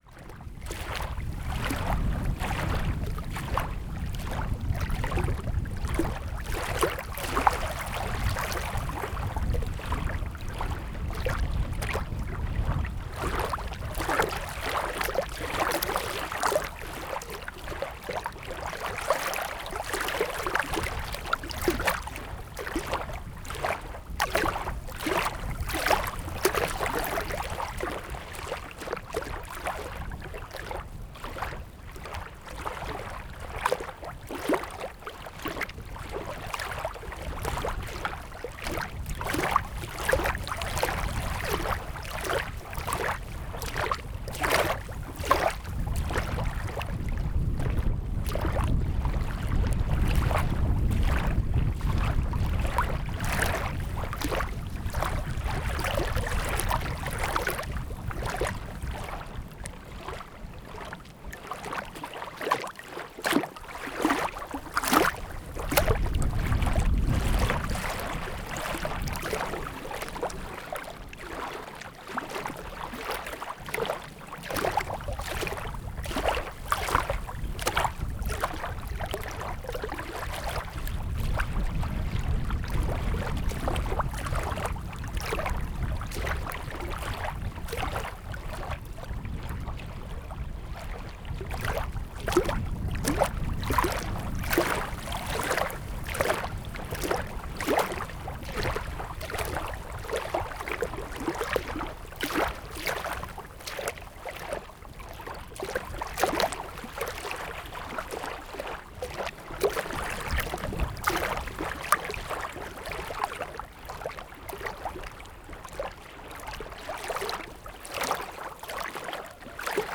maziarnia-lake-waves-and-wind-zoom-h2n.wav